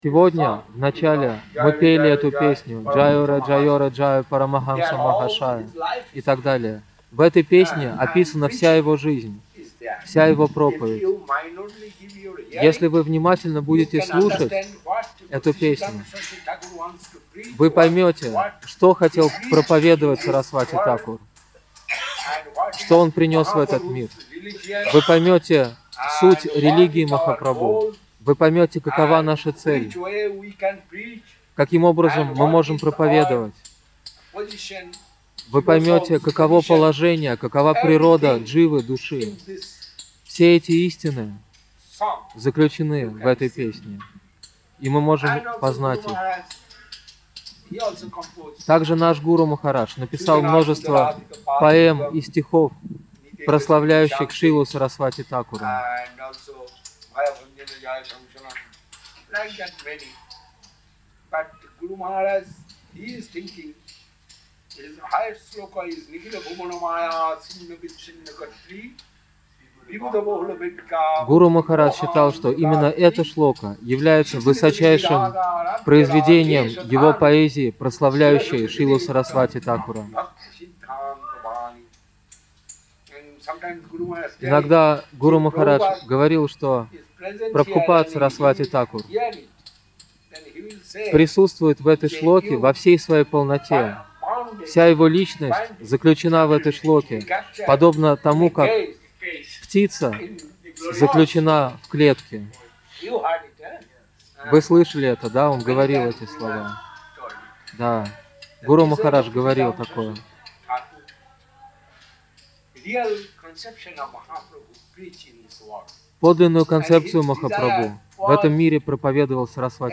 Place: SCSMath Nabadwip
Preaching